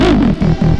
tekTTE63019acid-A.wav